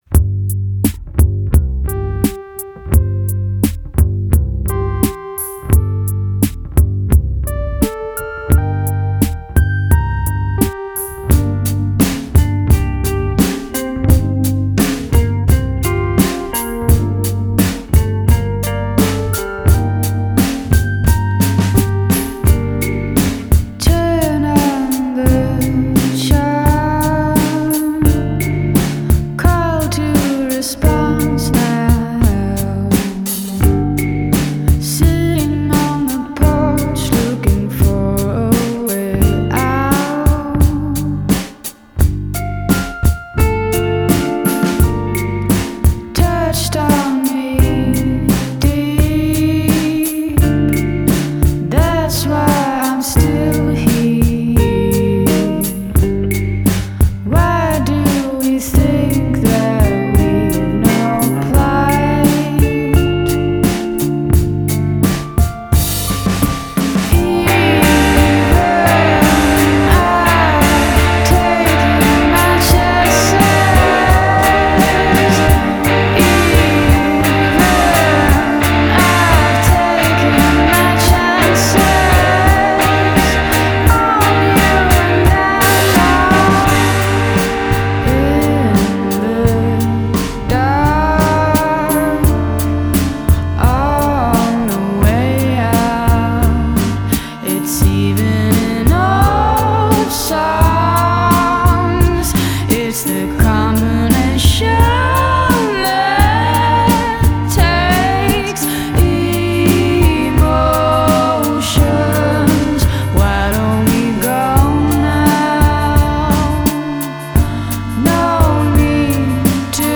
Ethereal, emotional, and gorgeous
awesome dynamics